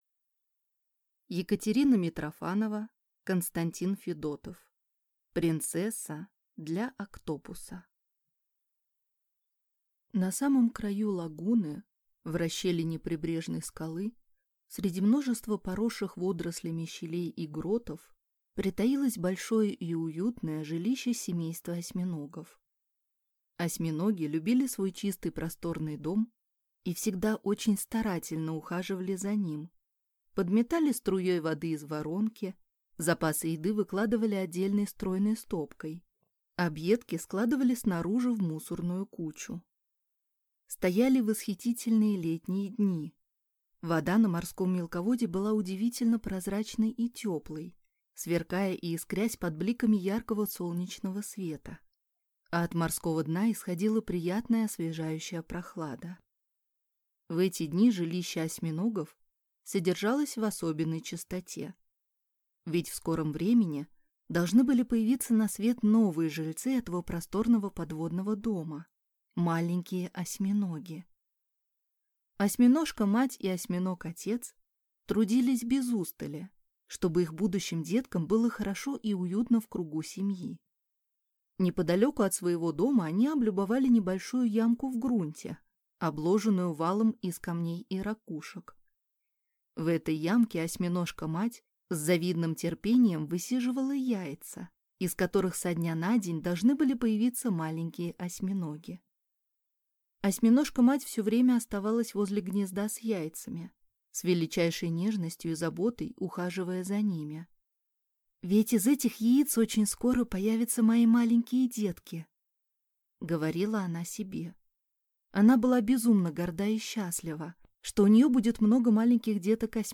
Аудиокнига Принцесса для Октопуса | Библиотека аудиокниг